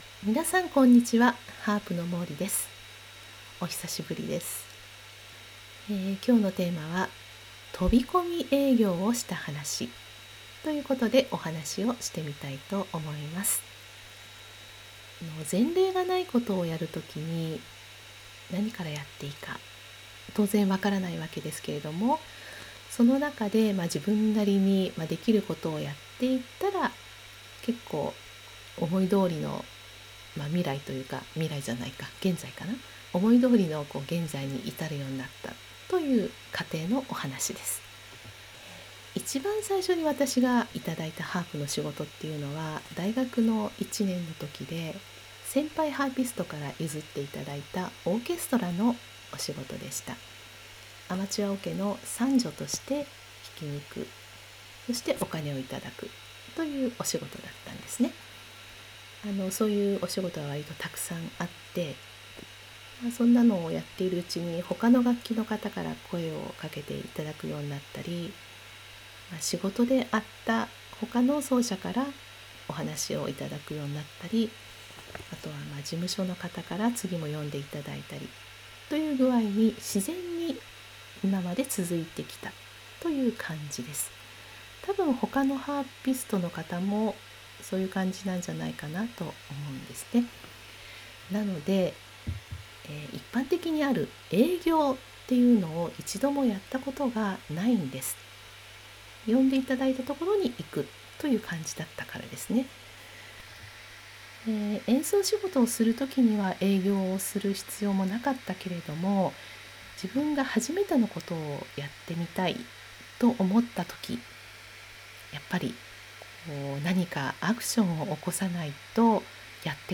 ※何故か雑音（ザー）が入ってしまいまして、とても聞きにくくなっております。